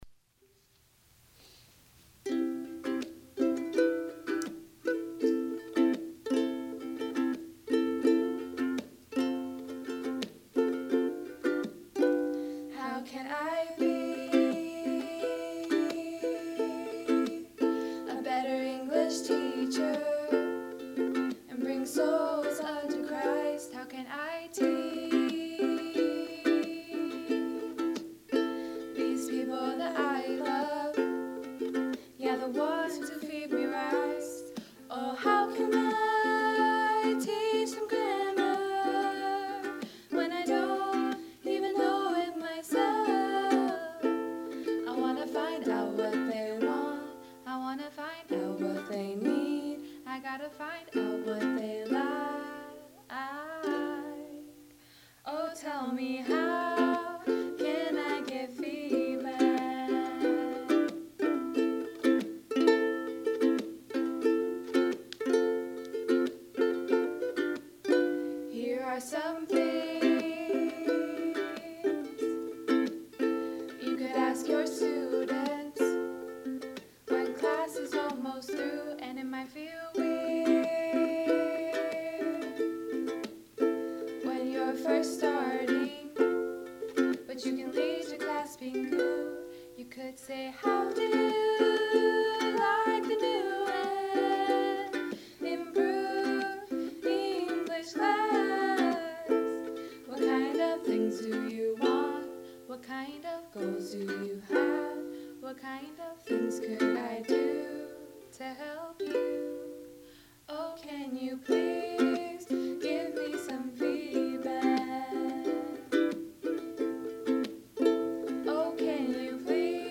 Song
Ukulele